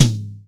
TOM     2A.wav